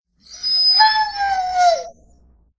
悲しいときの犬の鳴き声。
試聴とダウンロード 悲しいときの犬の鳴き声。何かが怖いのかな。